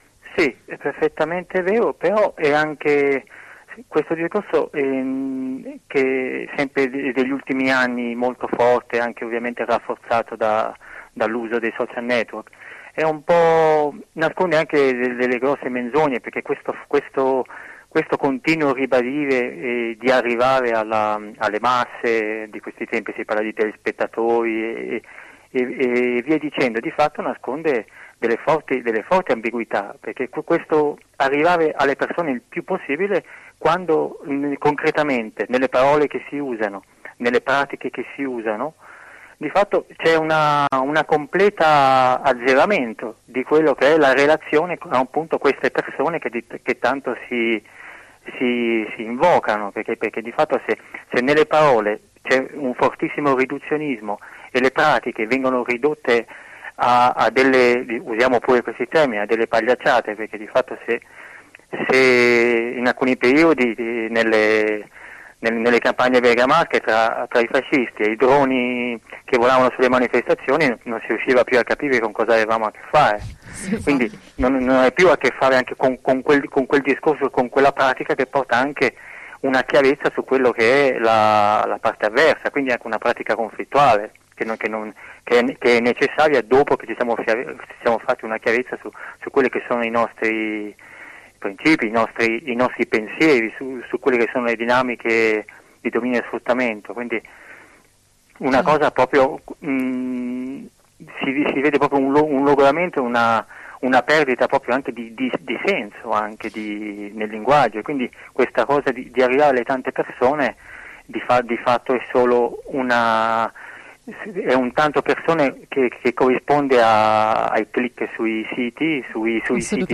Qui la diretta con un compagno del coordinamento Liber* Selvadec e l’appello alla settimana di mobilitazione